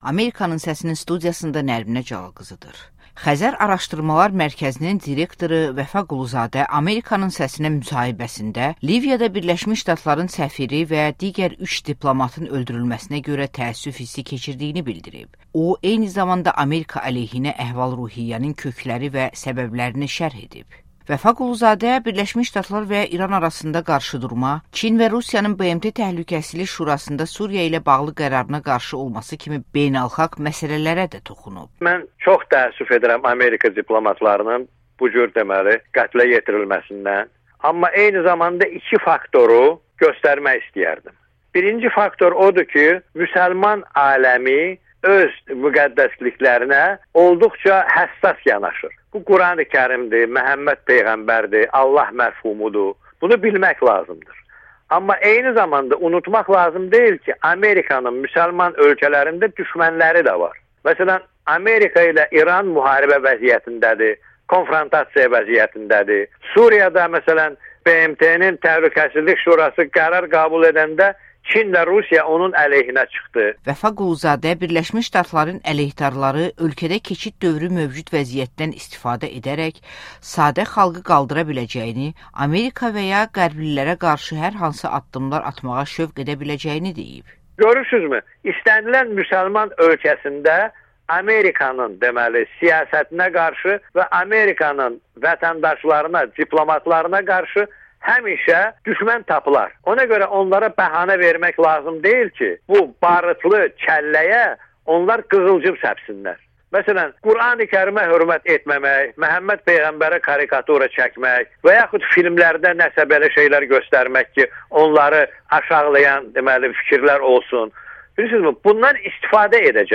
“Xəzər” Araşdırmaları Mərkəzinin direktoru Vəfa Quluzadə Amerikanın səsinə müsahibədə Liviyada Birləşmiş Ştatların səfiri və digər 3 diplomatın öldürülməsinə görə təəssüf hissi keçirdiyini bildirib